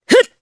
Morrah-Vox_Attack1_jp.wav